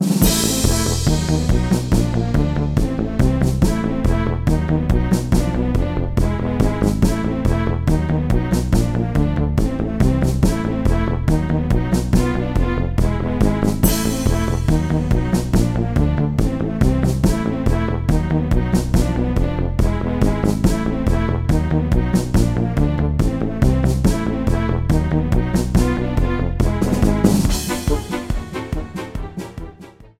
first phase boss battle music
Trimmed file to 30 seconds, applied fade-out